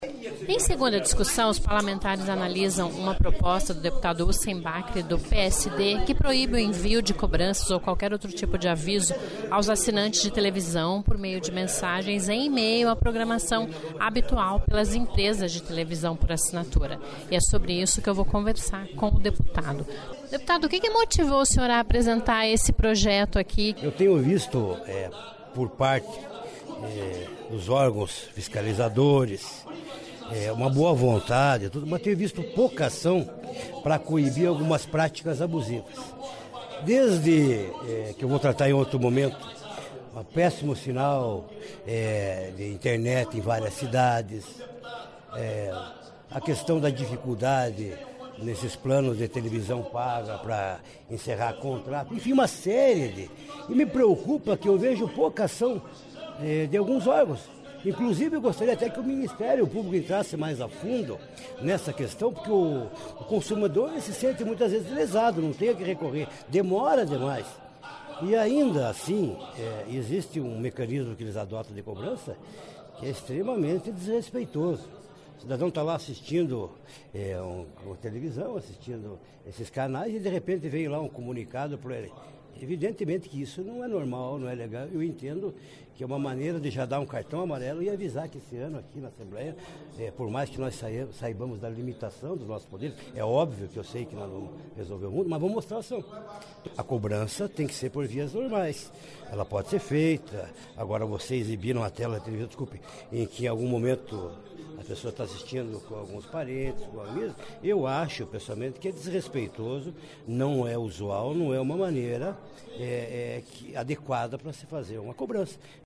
Ouça a entrevista com o deputado sobre o rpojeto de lei que tramita na Casa.